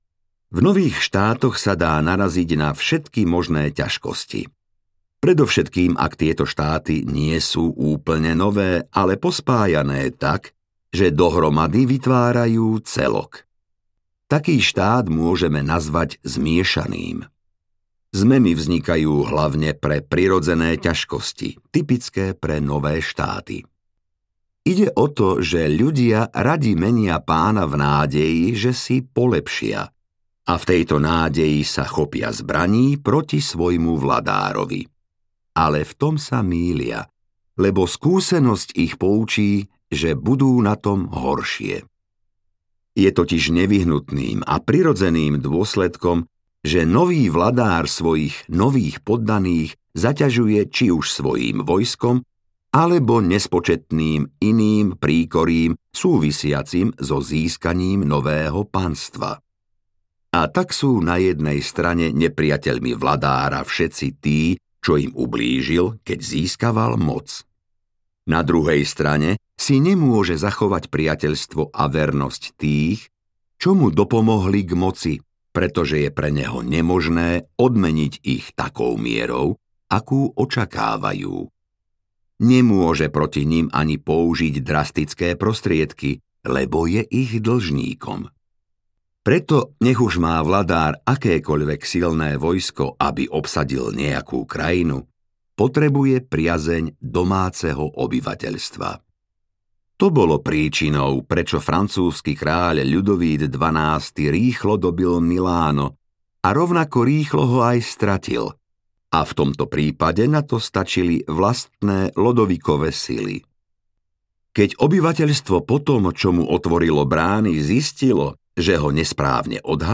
Audiokniha Vladár - Niccolò Machiavelli | ProgresGuru